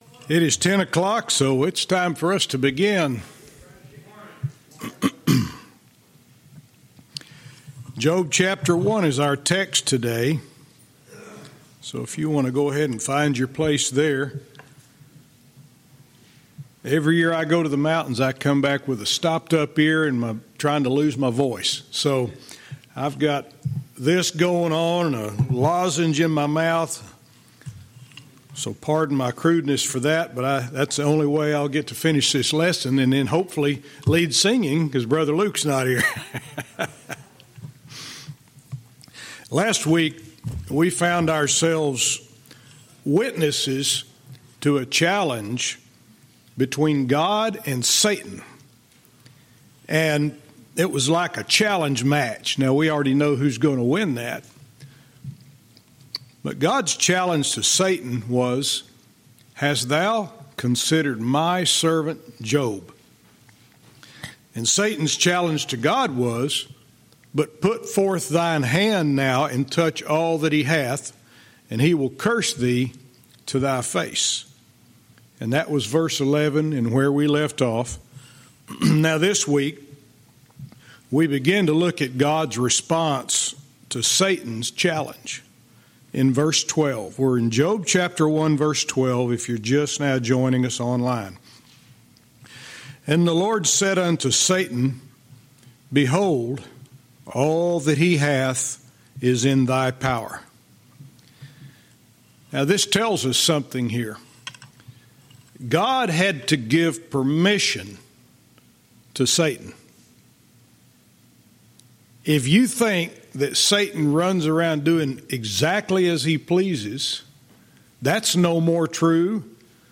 Verse by verse teaching - Job 1:12-19